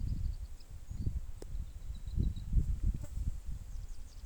Upucerthia dumetaria hypoleuca
English Name: Scale-throated Earthcreeper
Location or protected area: Ruta 307 entre El Infiernillo y Amaicha del Valle
Condition: Wild
Certainty: Recorded vocal